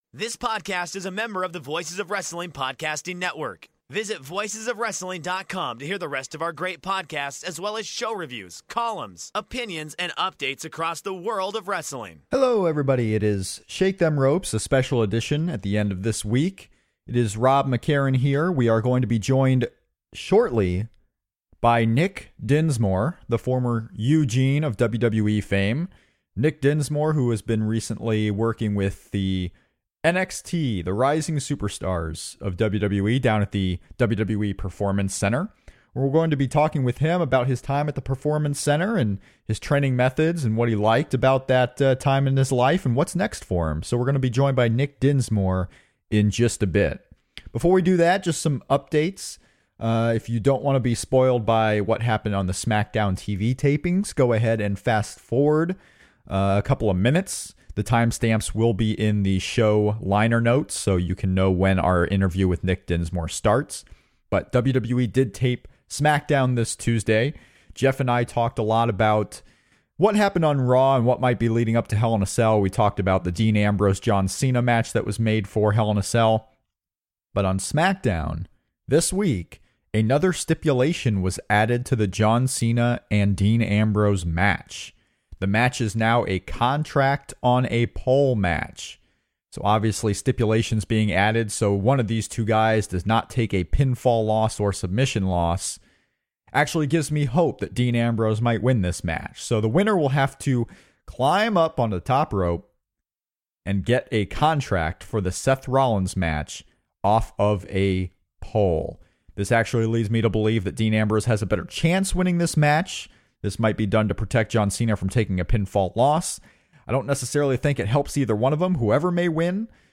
00:00-01:00: Intro 01:00-05:00: Smackdown News 05:00-07:02: Contact / Show Info 07:02-34:35: Nick Dinsmore Interview